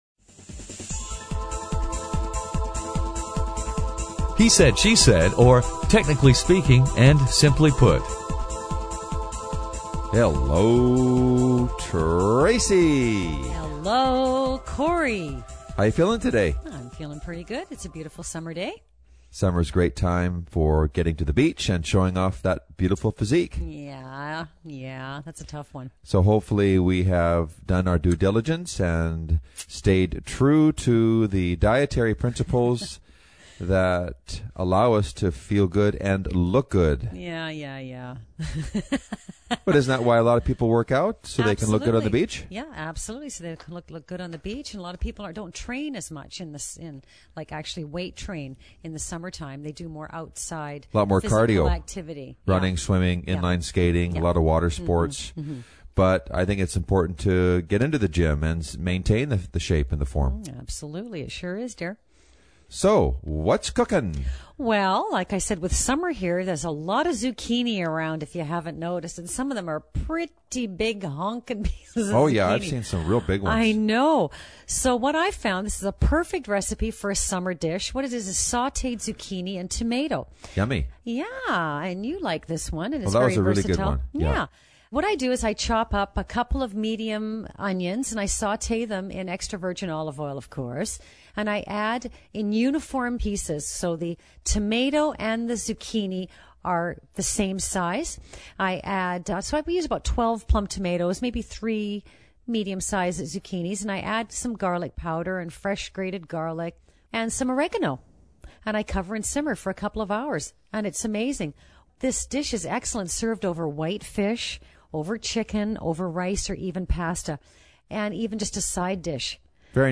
Hot Topic: The 24 Hour Money Challenge He-Said-She-Said is a spontaneous and humorous dialog